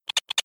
valve.wav